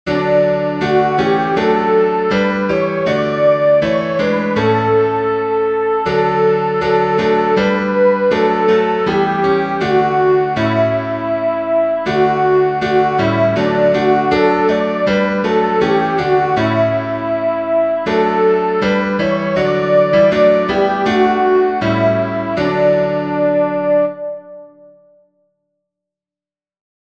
Soprano
duke_street_i_know_that_my_redeemer_lives-soprano.mp3